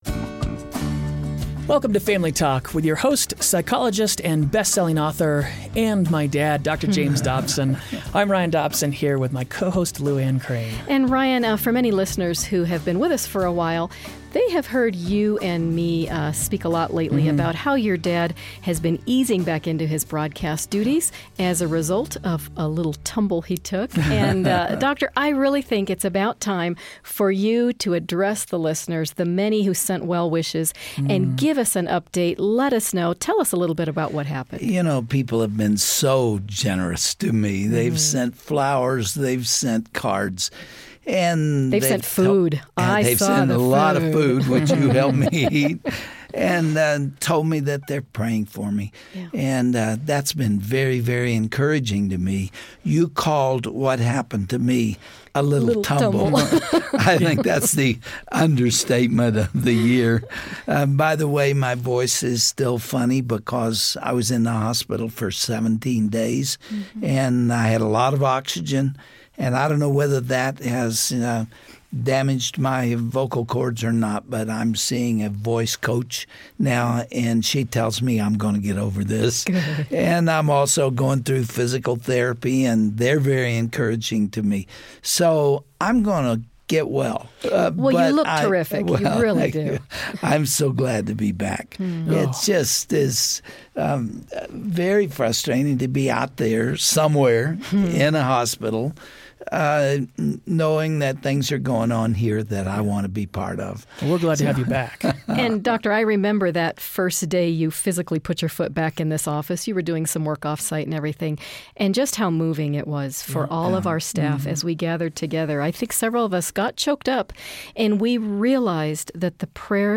When Dr. James Dobson suffered multiple broken bones from a horseback-riding accident a few weeks ago, Family Talk listeners responded with an overwhelming expression of love and prayers. Today our host returns to the microphone to say "Thank You!" and tell us first-hand what happened that day.